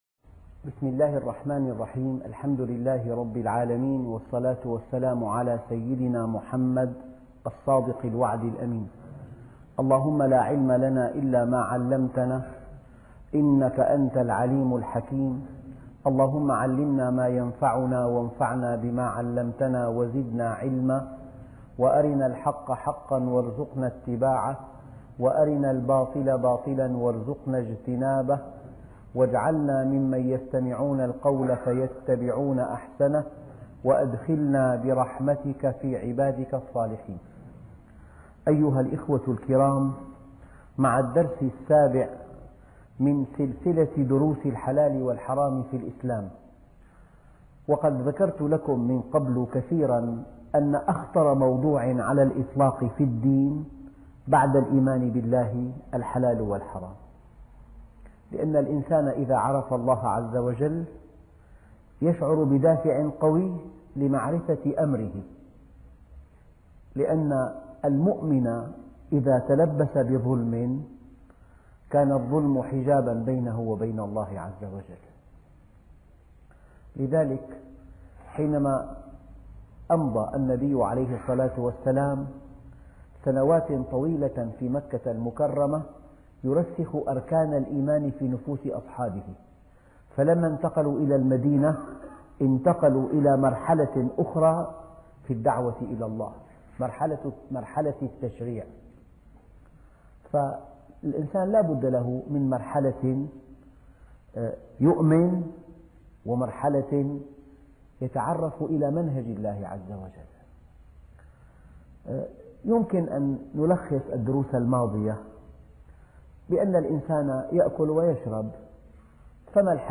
الدرس السابع الحلال والحرام في الكسب -الفقه الإسلامي عن الحلال والحرام - الشيخ محمد راتب النابلسي